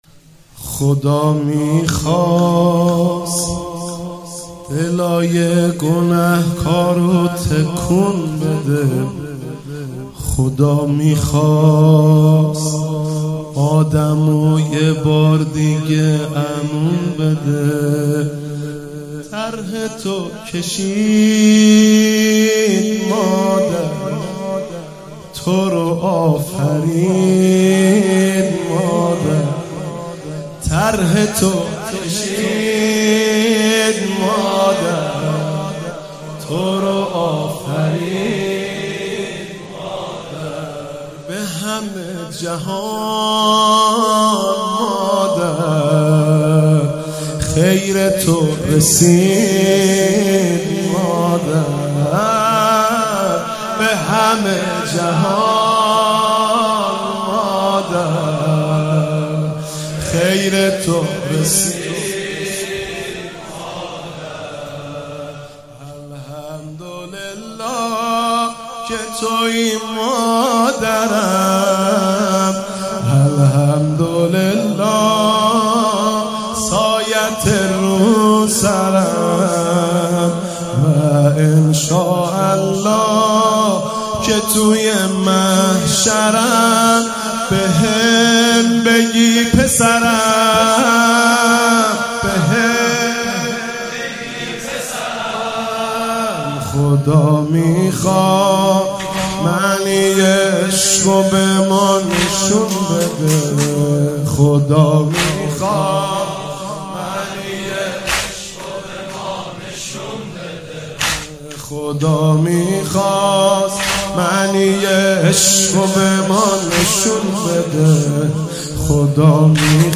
مداحی و نوحه
شب اول ایام فاطمیه دوم
حسینیه‌ هنر تهران